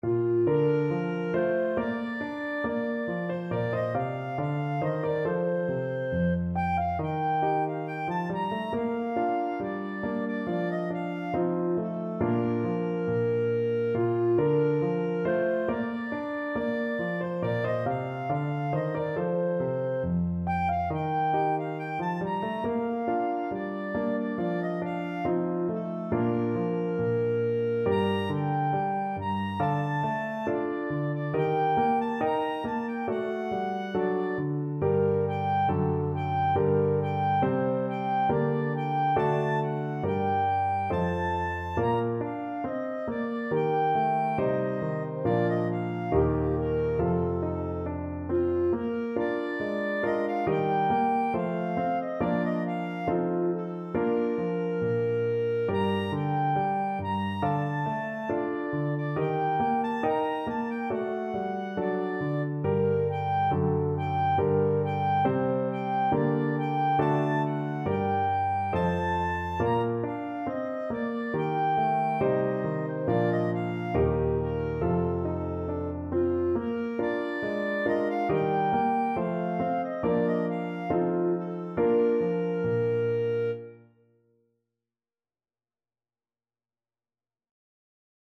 Clarinet
2/2 (View more 2/2 Music)
Bb major (Sounding Pitch) C major (Clarinet in Bb) (View more Bb major Music for Clarinet )
Steady two in a bar =c.69
Classical (View more Classical Clarinet Music)